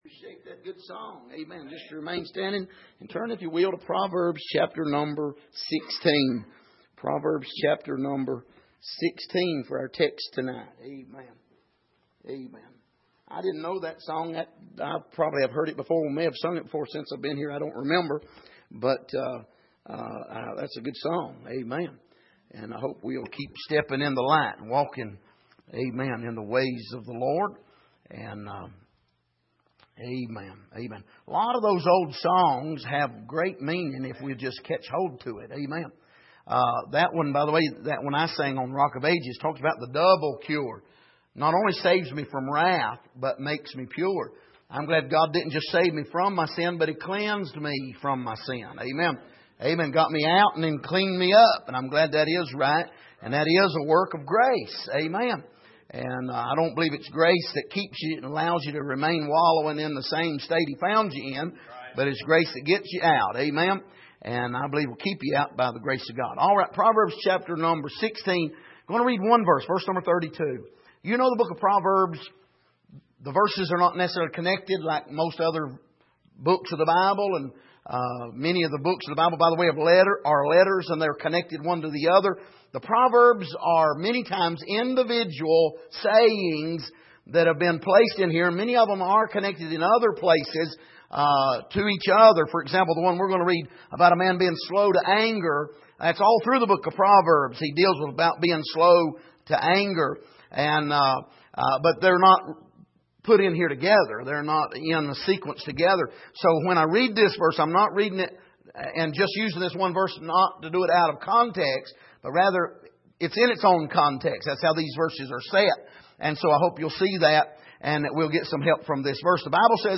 Passage: Proverbs 16:32 Service: Midweek